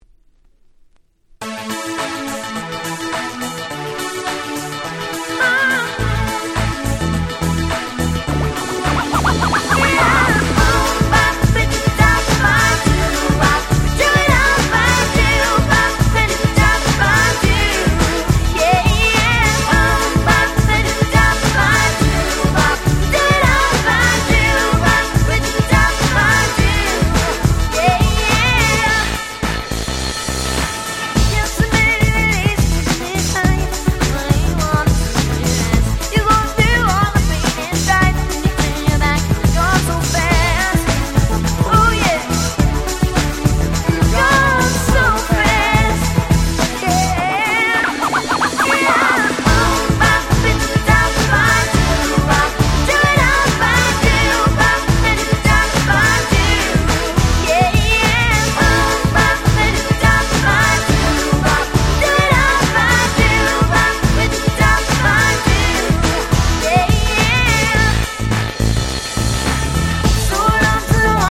97' 世界的大ヒットPops !!